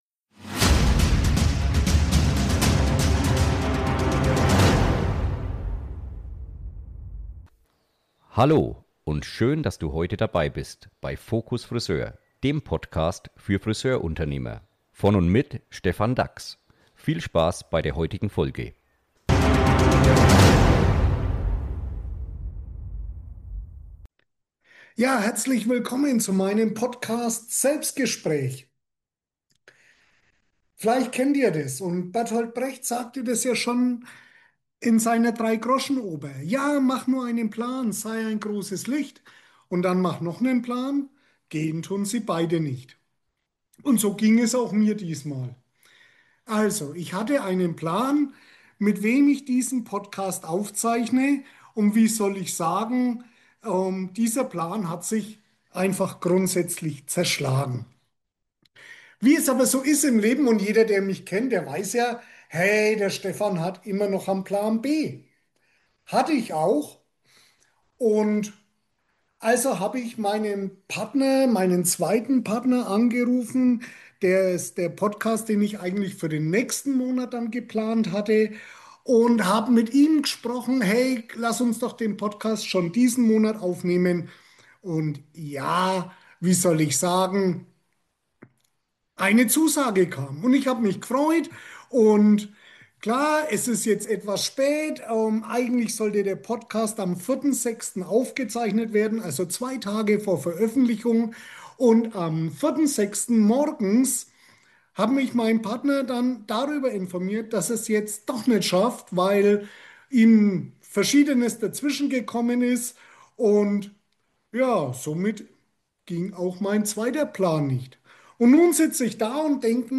Selbstgespräch – Wenn Plan A & B scheitern ~ Focus Friseur Podcast